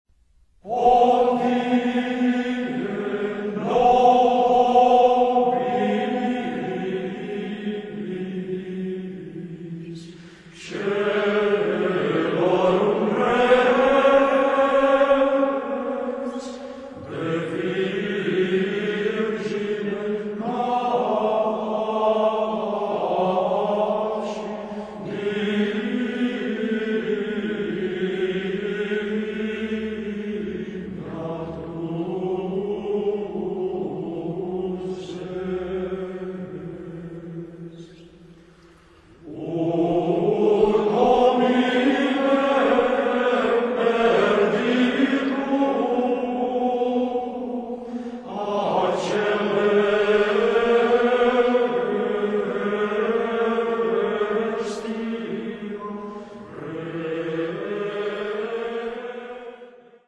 Choir of the Benedictine Abbey Montserrat monastery – Gregorian Chant For Christmas